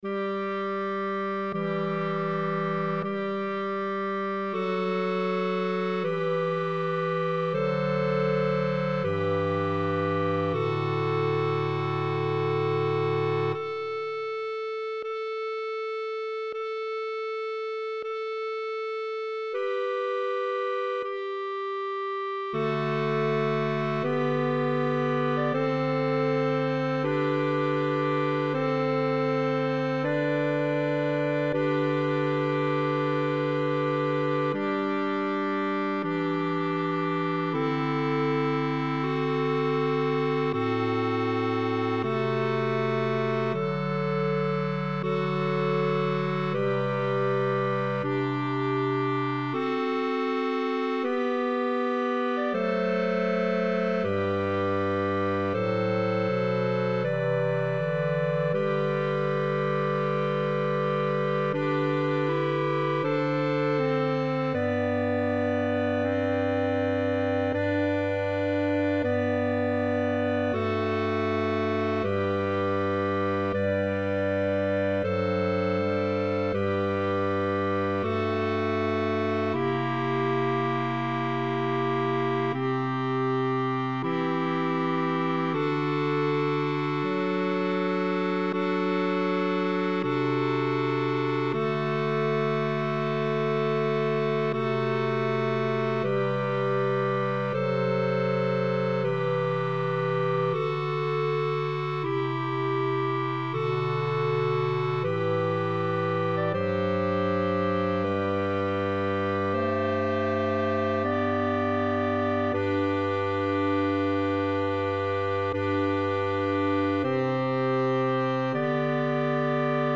Partitura, cor mixt (pdf): Apărătoare Doamnă